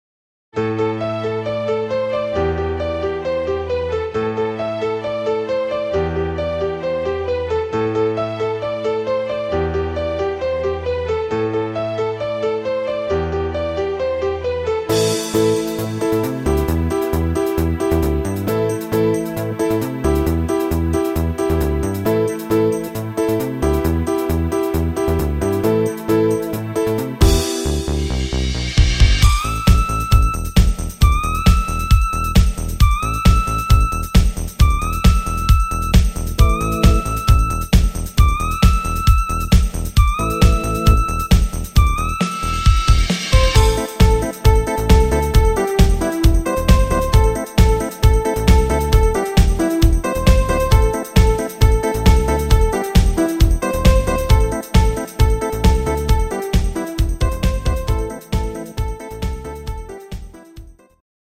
Rhythmus  Dancefloor